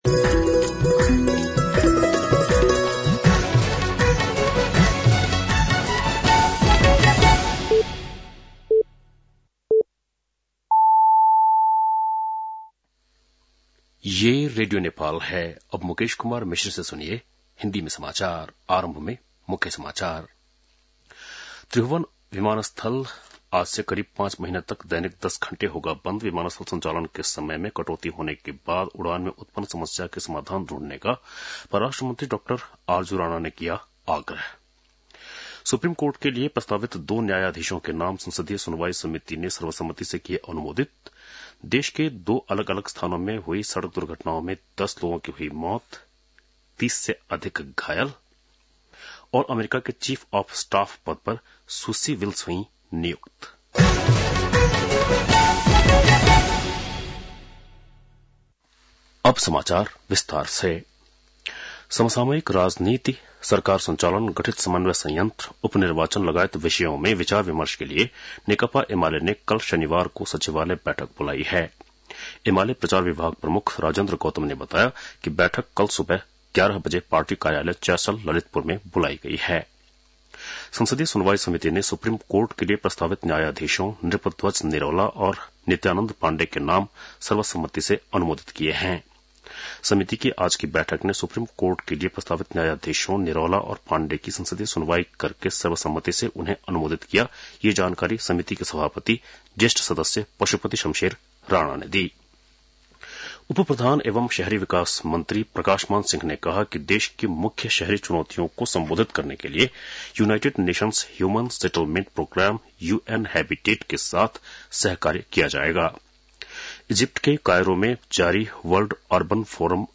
बेलुकी १० बजेको हिन्दी समाचार : २४ कार्तिक , २०८१